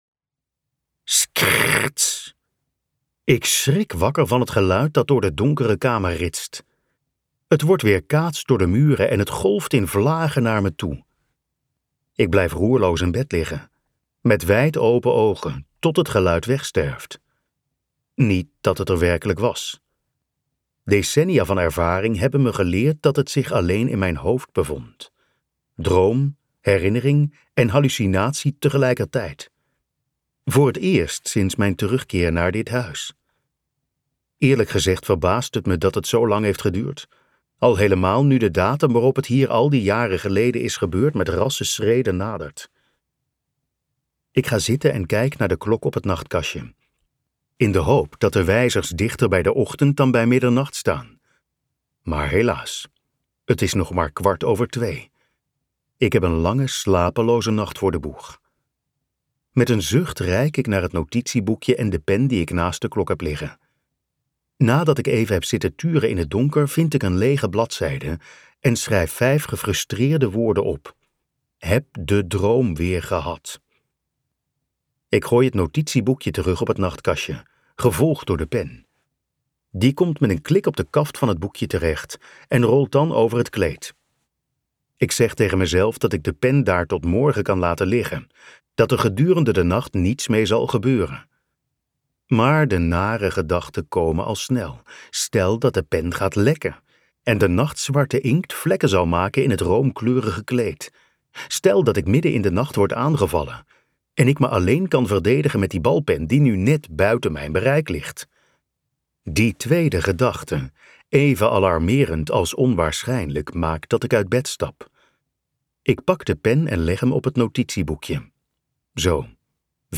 Ambo|Anthos uitgevers - Midden in de nacht luisterboek